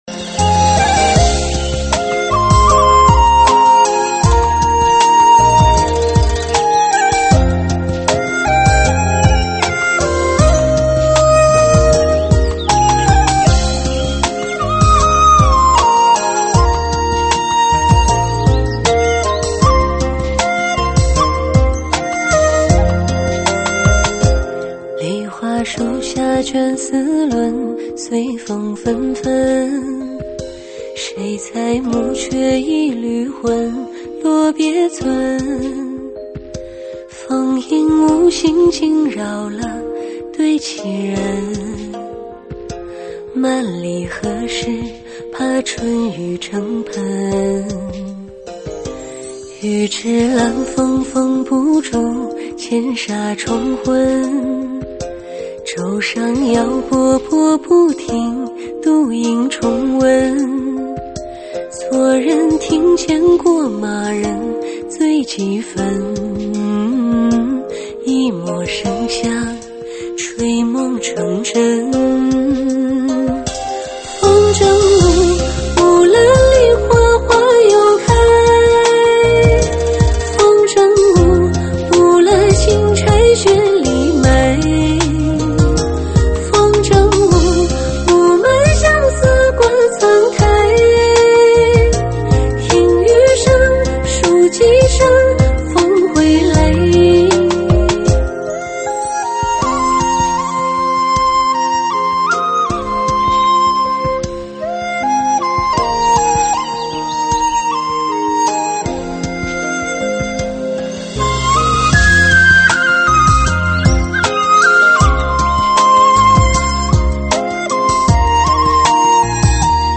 [现场串烧] 福建尤溪《抖.音热歌V古风V老歌翻唱。
舞曲类别：现场串烧